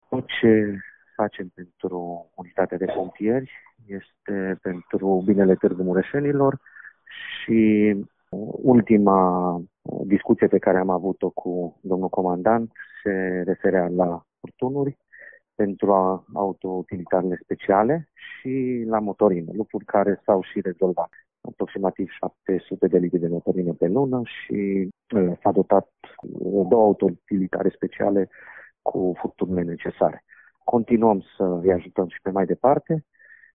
În paralel, Primăria și Consiliul Local s-au angajat să sprijine activitatea ISU Mureș cu 700 de litri de combustibil pe lună, a precizat viceprimarul municipiului Tg.-Mureș, Claudiu Maior.